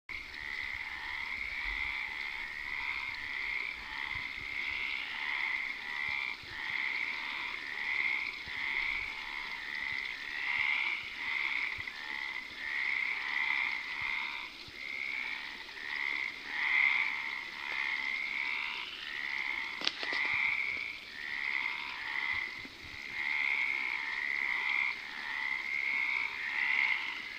Hyla meridionalis: canto    Nuova Discussione
Sotto casa mia c'è un ruscelletto con un po' di verde, e le nostre brave raganelle (o sarebbe meglio dire raganelli) si cimentano in concerti senza fine dalla sera alla mattina.
Con un paio di bermuda e il favore del buio ho registrato il loro canto...ma non è che sia una registrazione di gran qualità, l'ho eseguita col mio cellulare!
Hyla meridionalis: canto Hyla meridionalis.wma
La qualità non mi sembra così male, e poi rende benissimo l'idea (anche se io dal vero non l'ho mai sentita, questo suono mi sembra molto chiaro e naturale).
Il rumore dell'acqua corrente del ruscello in sottofondo "inquina" un po' il richiamo, ma direi che tutto sommato si sente benino.
non è affatto male la qualità , ed il canto sembra molto diverso rispetto ad H. intermedia!